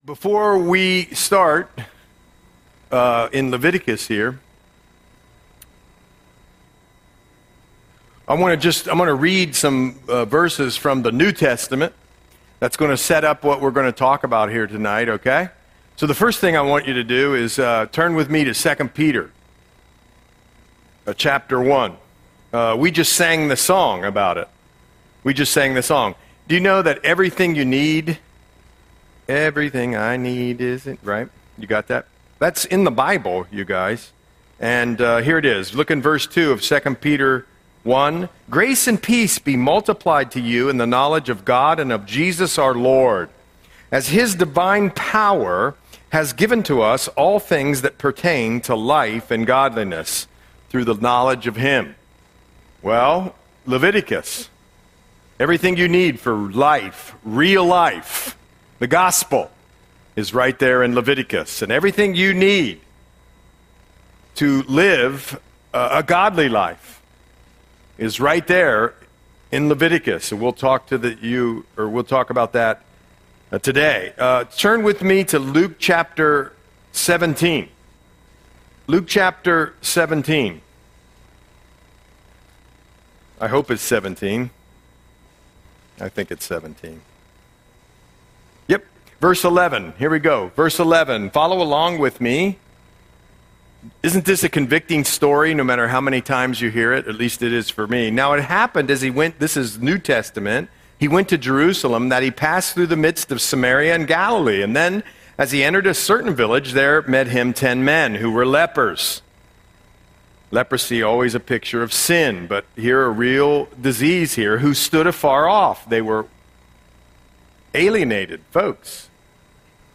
Audio Sermon - September 3, 2025